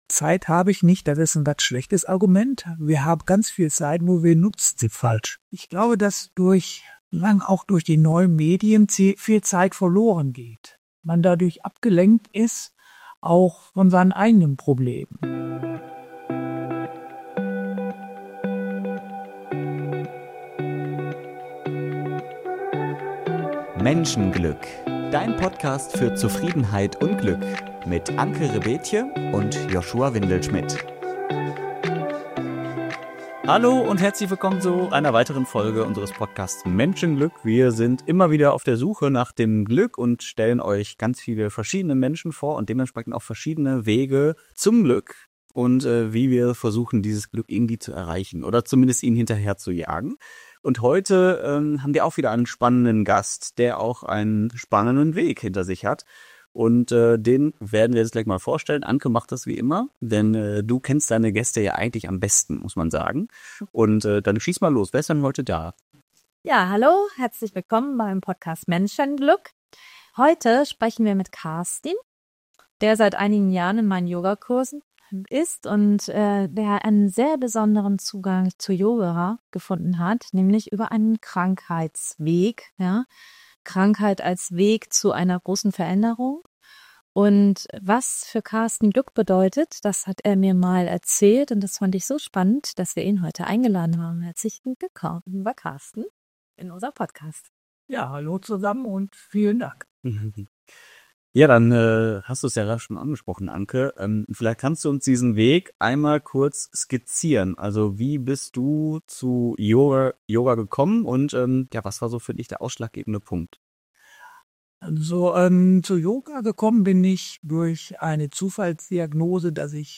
Ein ruhiges, ehrliches Gespräch über Gesundheit, Achtsamkeit und das, was uns im Leben wirklich trägt.